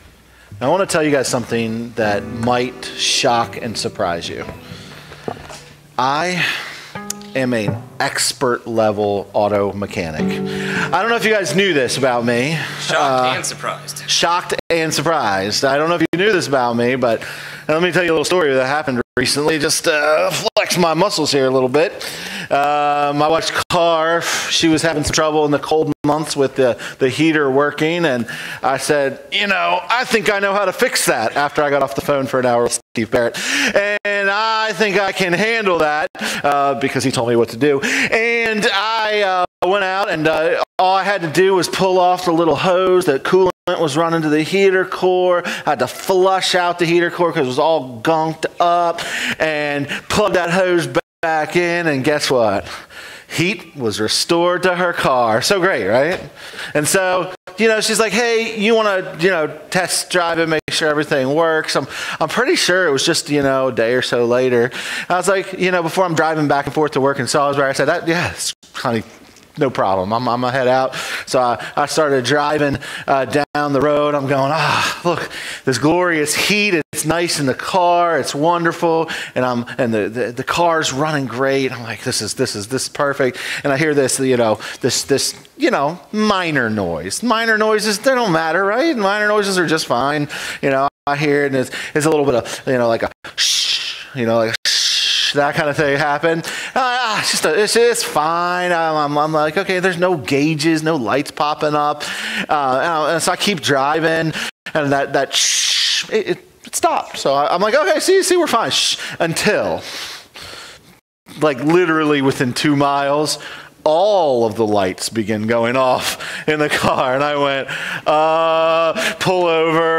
All Sermons , Revealed Book Revelation Watch Listen Save Revelation unmasks the true enemies of Christ and His Church—not to scare us, but to strengthen us. These enemies aim to deceive, distract, and discourage—but the Lamb has already overcome.